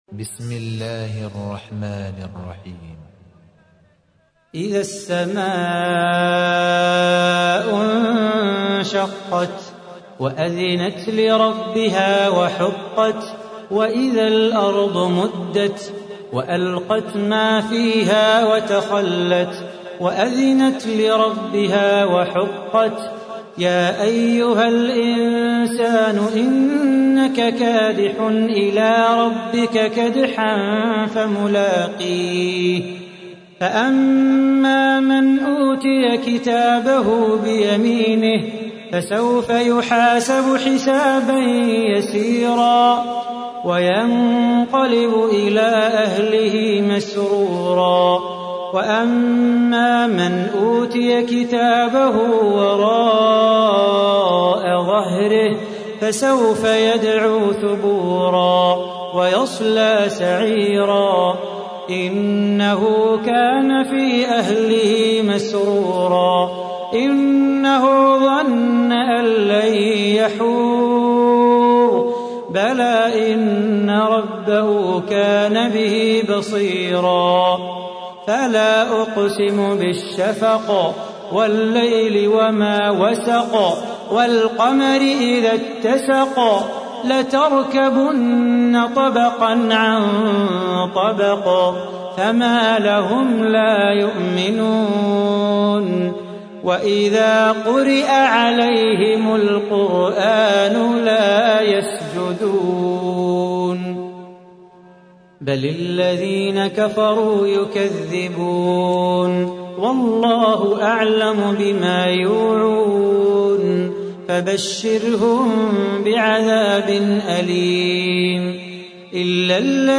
تحميل : 84. سورة الانشقاق / القارئ صلاح بو خاطر / القرآن الكريم / موقع يا حسين